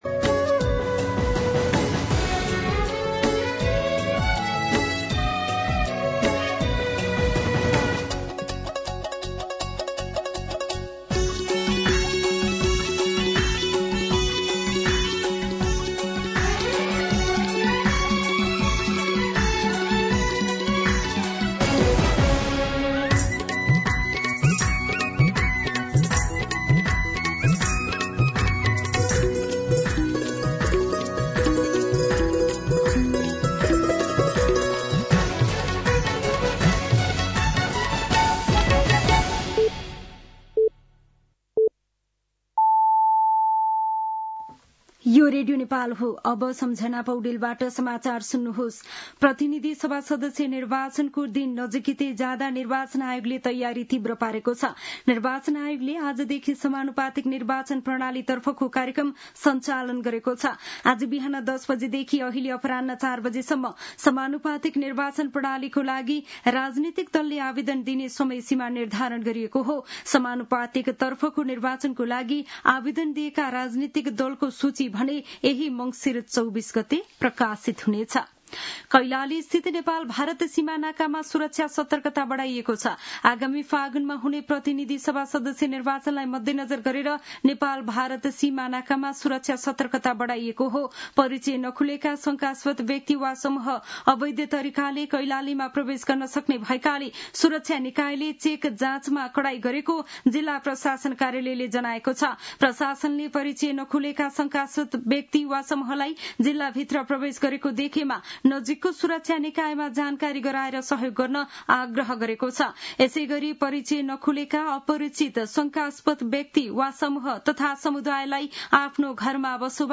दिउँसो ४ बजेको नेपाली समाचार : २१ मंसिर , २०८२
4-pm-News-8-21.mp3